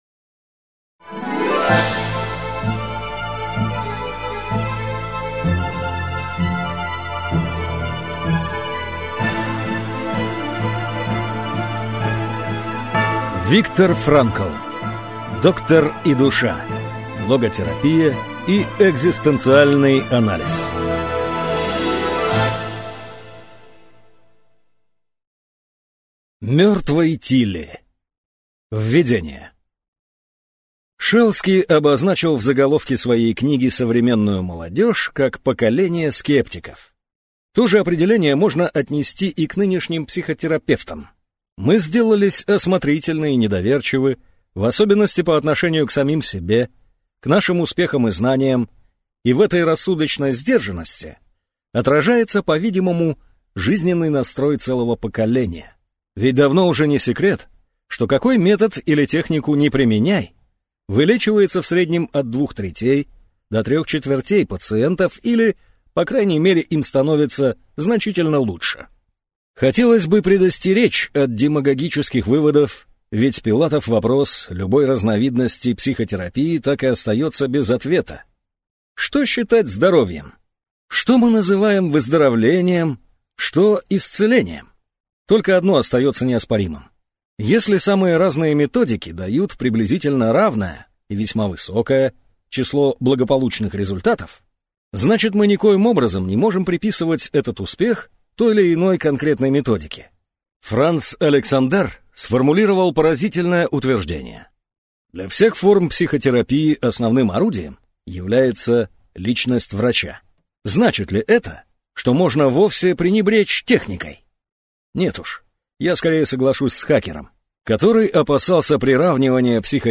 Аудиокнига Доктор и душа: Логотерапия и экзистенциальный анализ | Библиотека аудиокниг